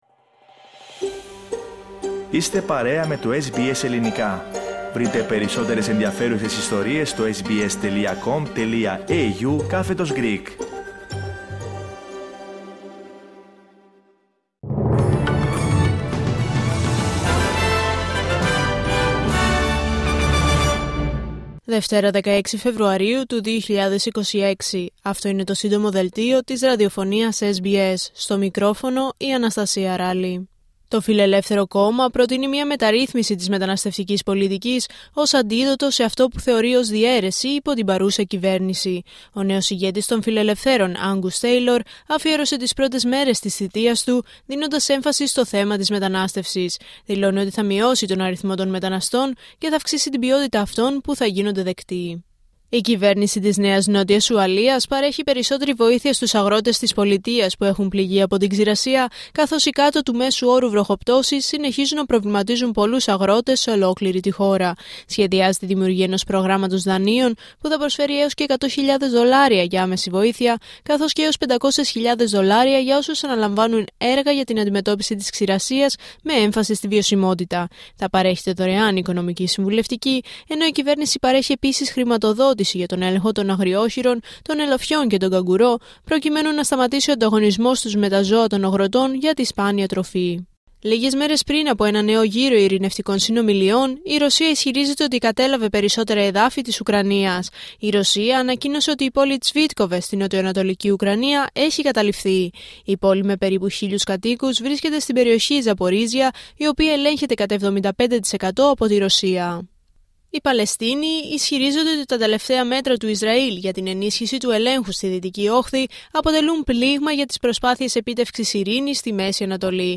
H επικαιρότητα έως αυτή την ώρα στην Αυστραλία, την Ελλάδα, την Κύπρο και τον κόσμο στο Σύντομο Δελτίο Ειδήσεων της Δευτέρας 16 Φεβρουαρίου 2026.